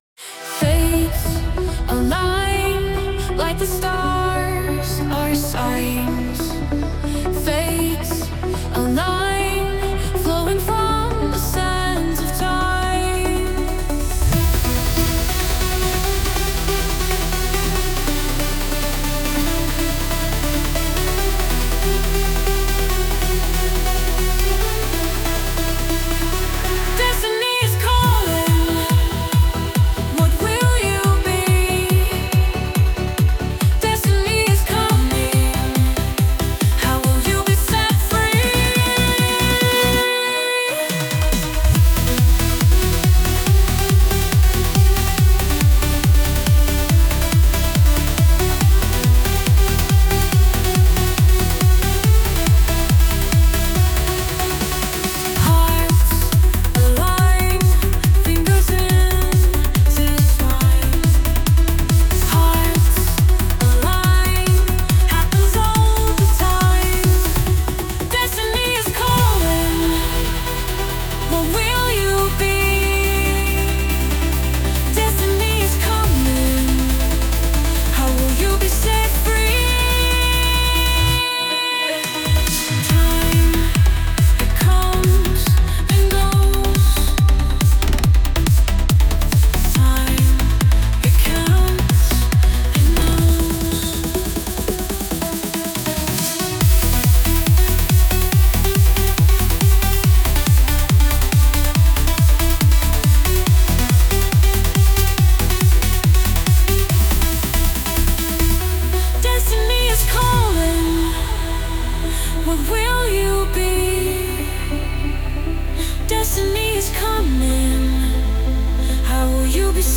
A trance track given to knowing as one.......